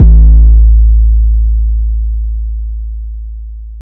BWB 808 (36).wav